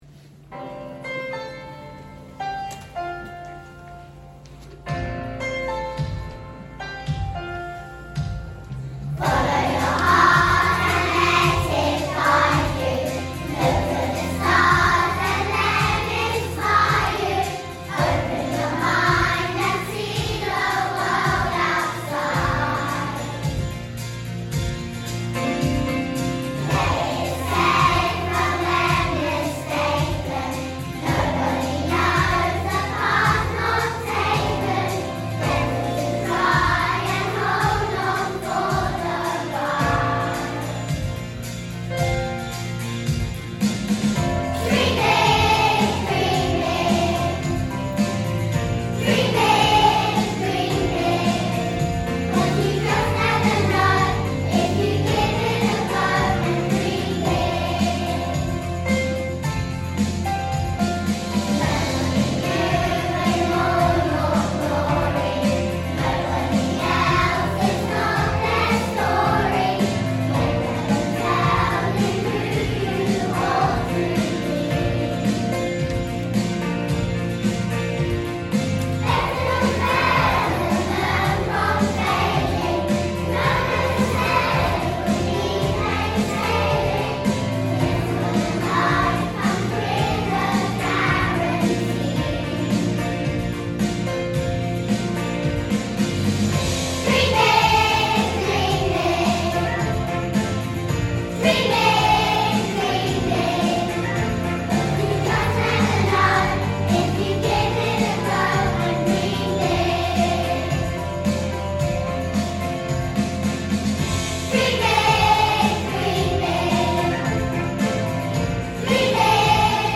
Dream Big | Y2/3 Choir